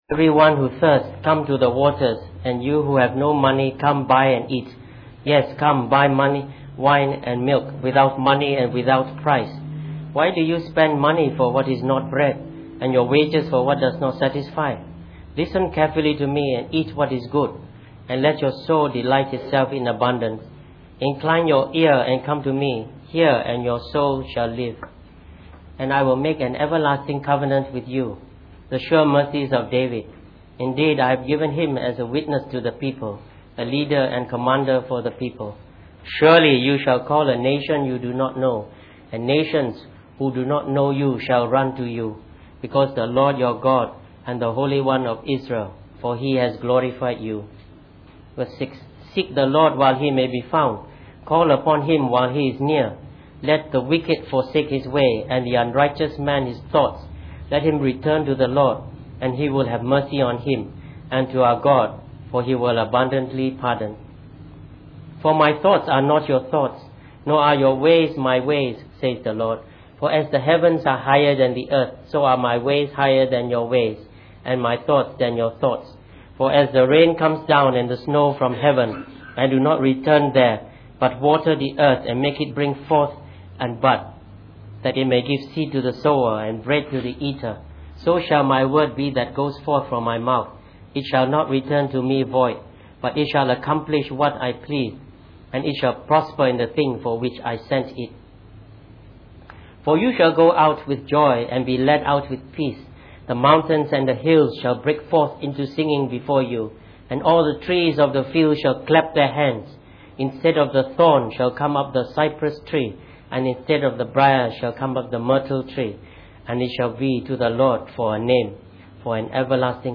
Preached on the 27th of January 2013.